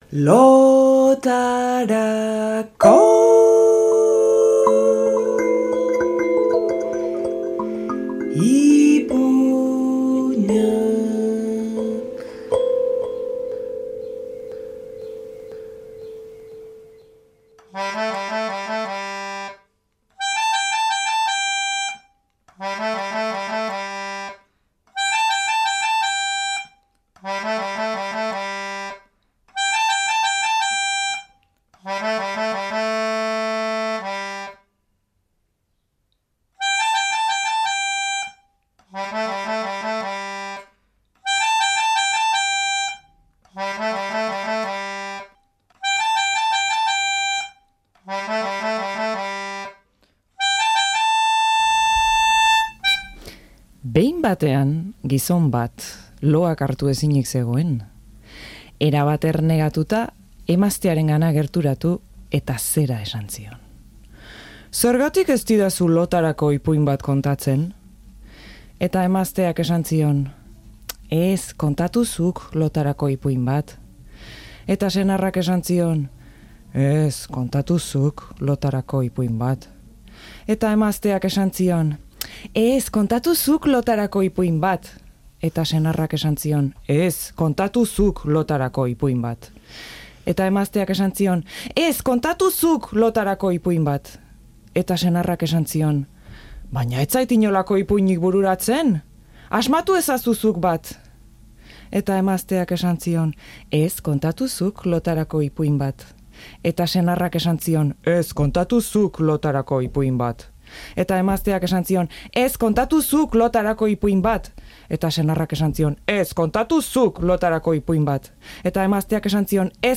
lotarako ipuina